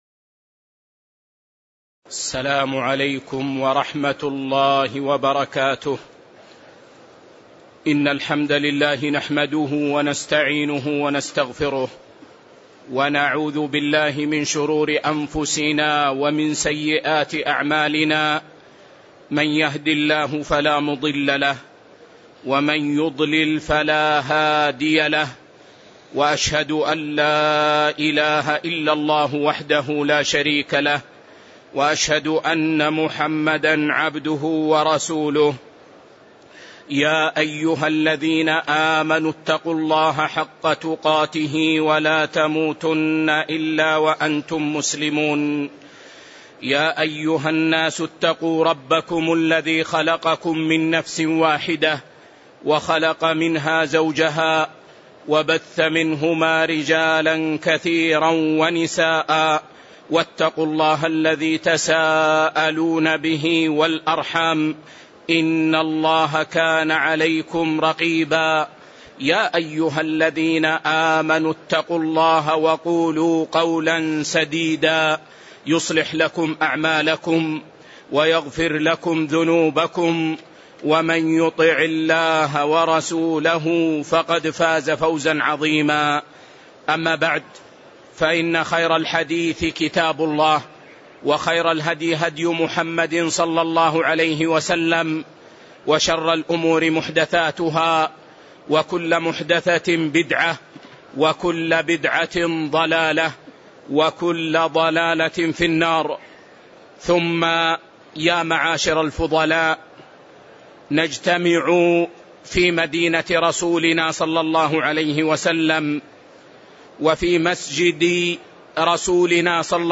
الدروس العلمية بالمسجد الحرام والمسجد النبوي